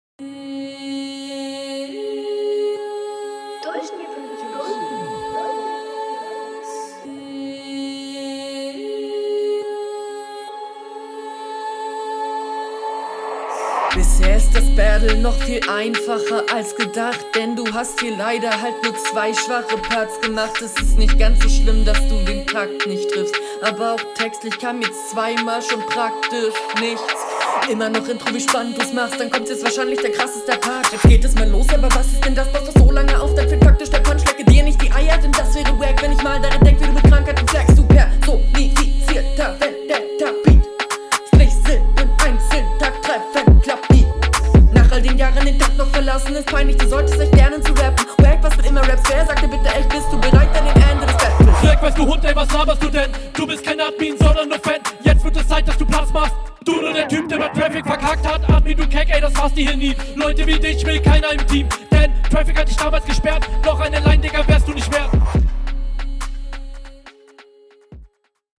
Flow kommt etwas gehetzt, eig Stabil, …